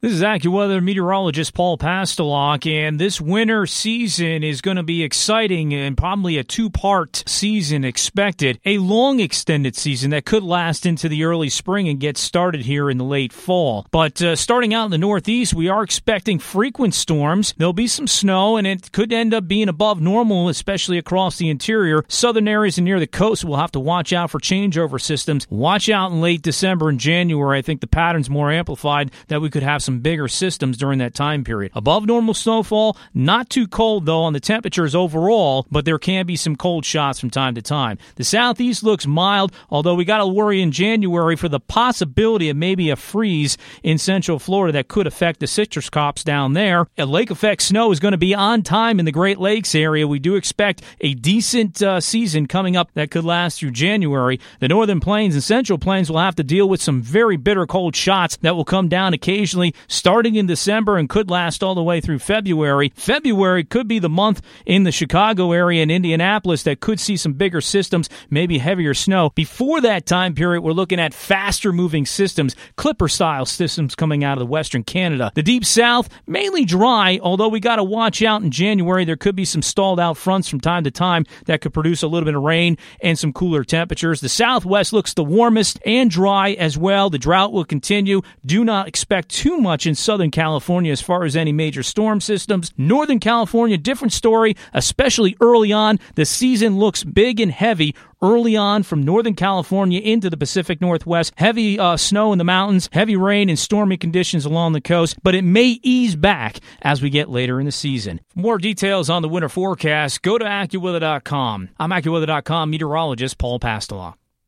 recorded a short report summarizing the AccuWeather Winter Forecast for the US.